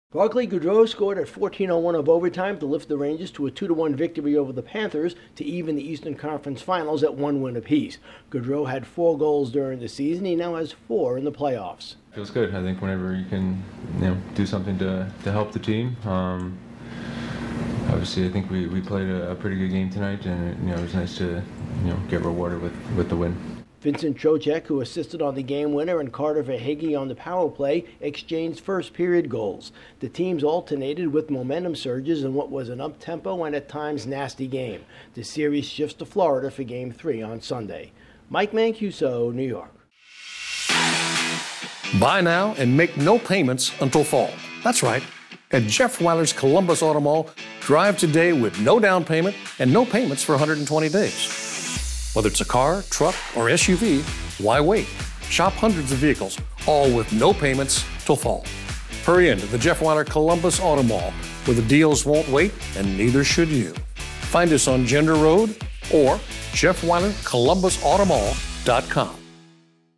A game-ending goal sends the Rangers past the Panthers. Correspondent